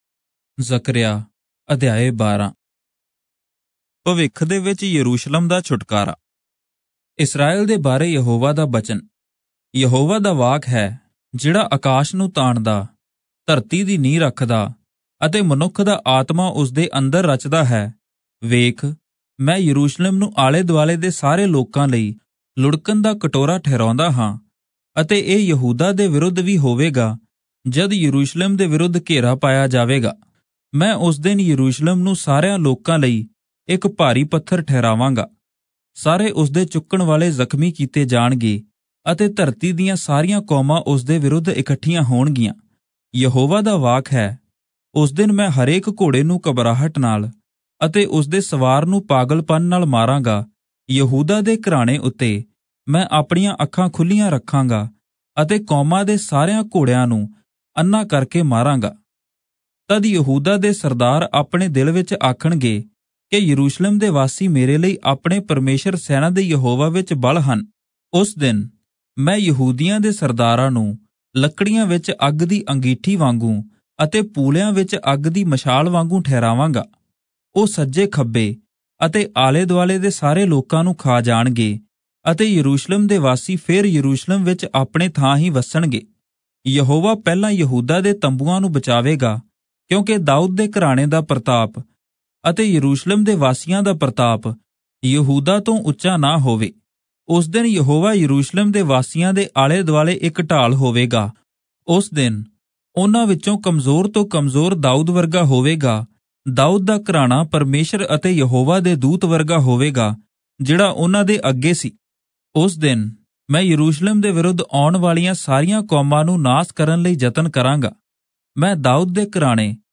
Punjabi Audio Bible - Zechariah 14 in Irvpa bible version